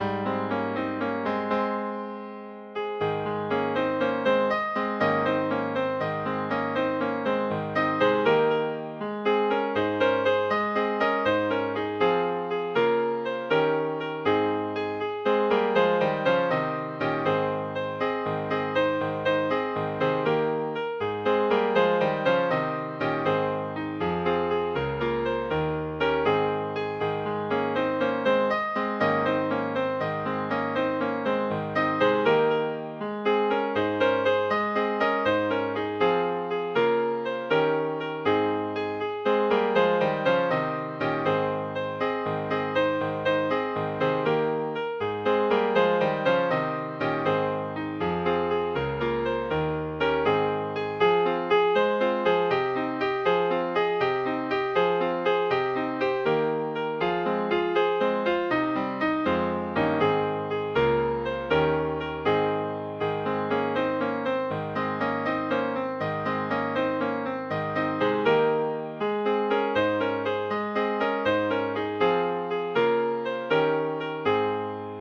This is an old Gaelic tune